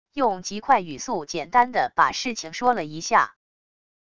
用极快语速简单的把事情说了一下wav音频